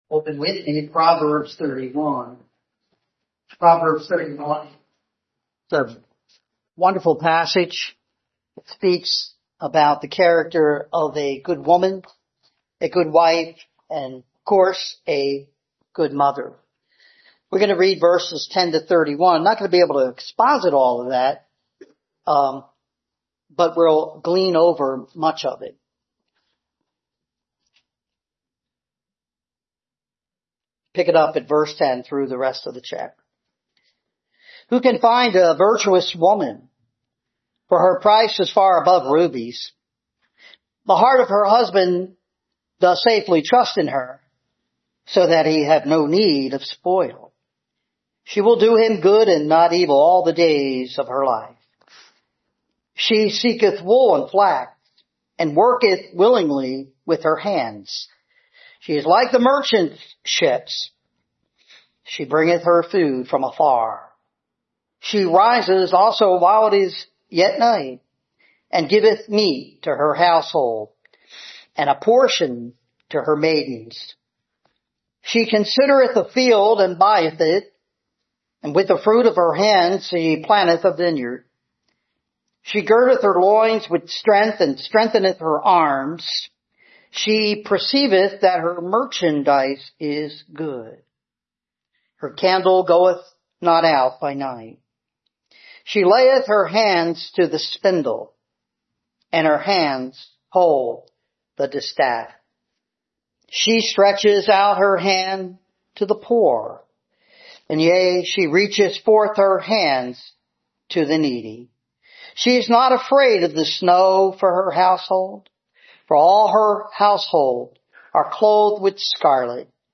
Passage: Proverbs 31:10-31 Service Type: Sunday Morning